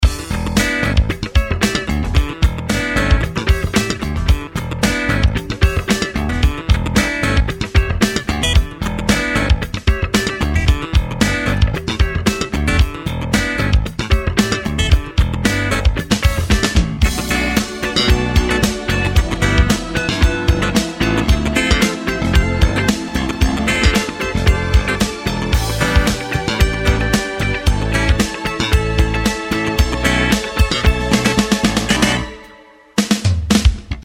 Funky Slap Jam